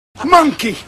monkey.mp3